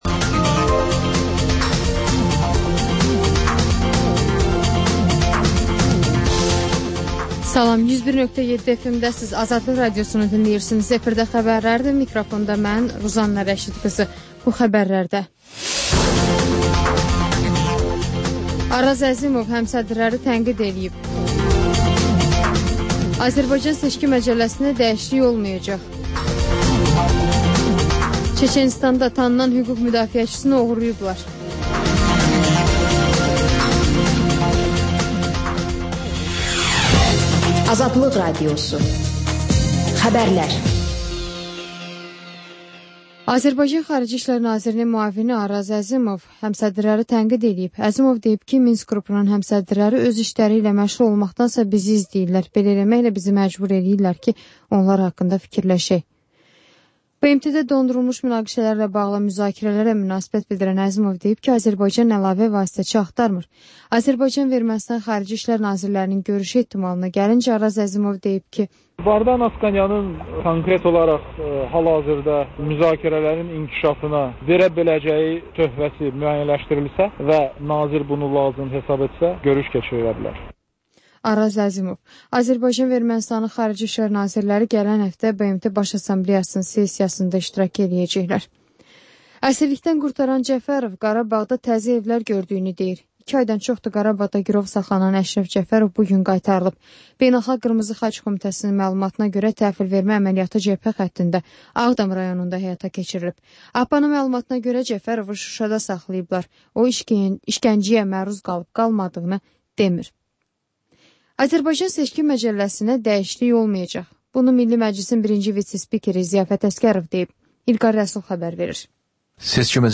Xəbərlər, müsahibələr, hadisələrin müzakirəsi, təhlillər, sonda XÜSUSİ REPORTAJ rubrikası: Ölkənin ictimai-siyasi həyatına dair müxbir araşdırmaları